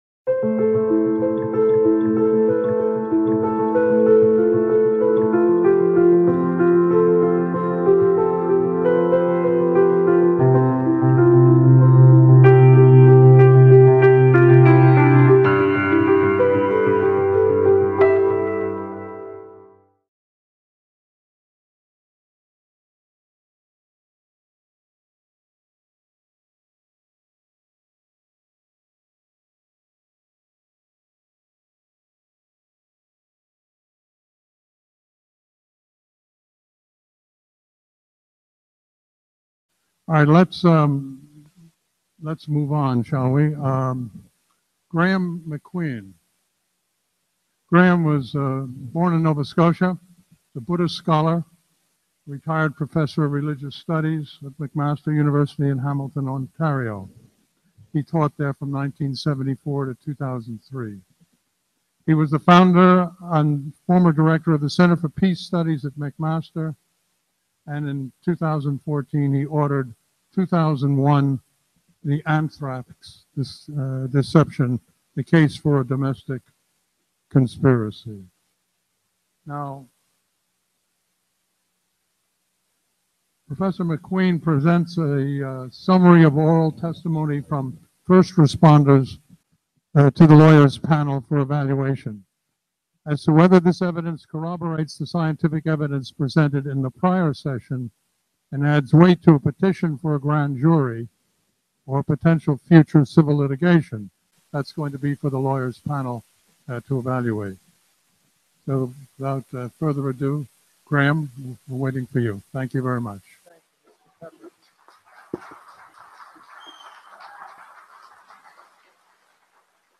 The above link is to a mp3 recording of that film. Sound quality is intermittently very poor in the first 3:10 min:secs and is fine from then on.